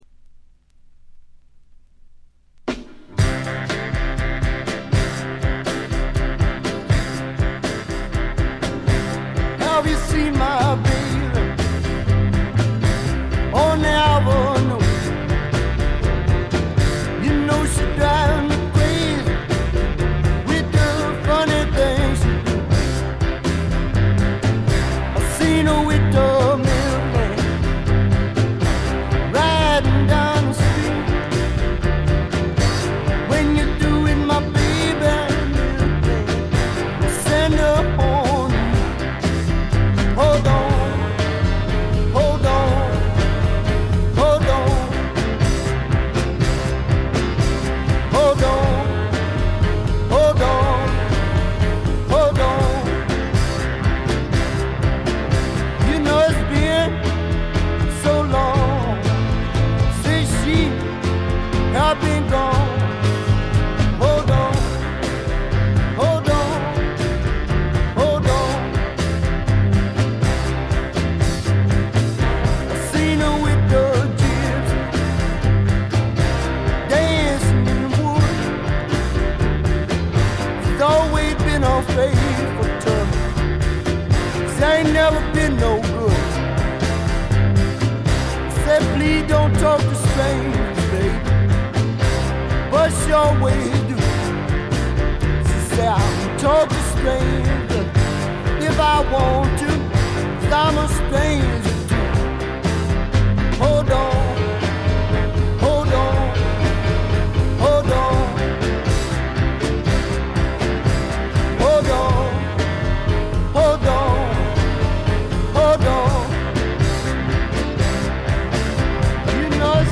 静音部で軽微なバックグラウンドノイズが少し。
試聴曲は現品からの取り込み音源です。
vocals, piano